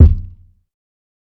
Kicks
TC3Kick12.wav